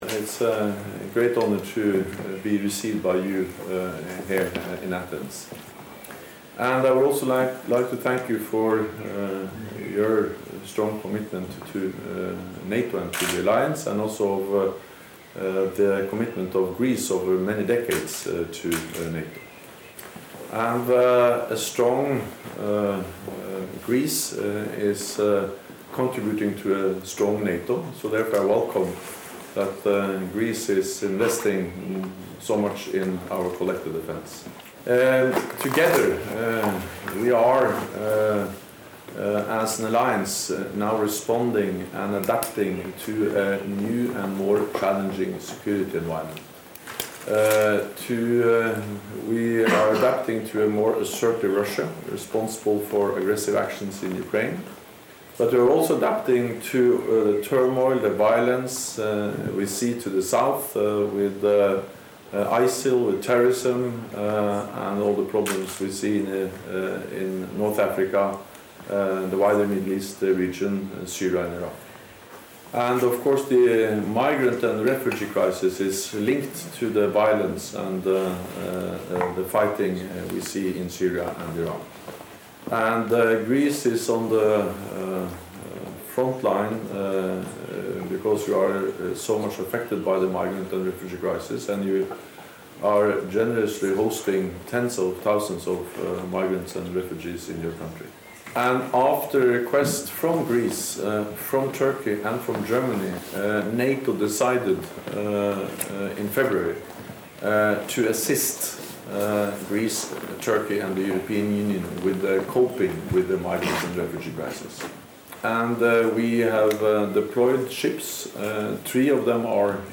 Press statement by NATO Secretary General Jens Stoltenberg with the President of Greece, Prokopis Pavlopoulos